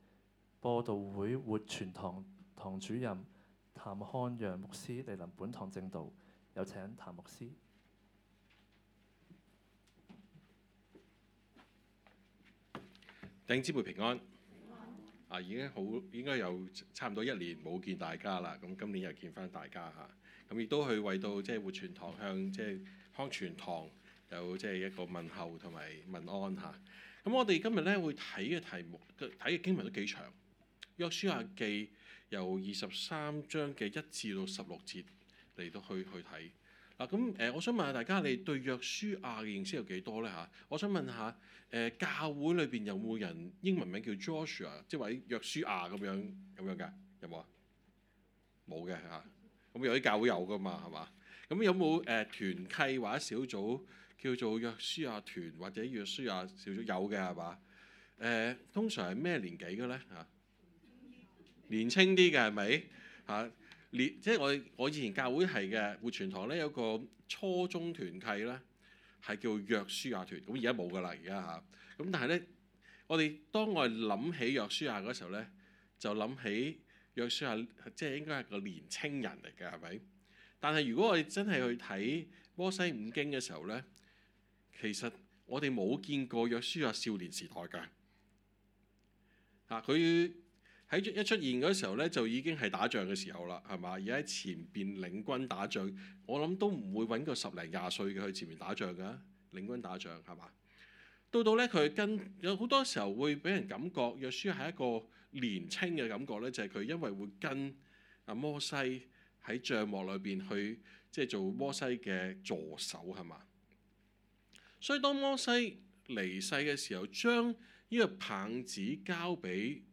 崇拜講道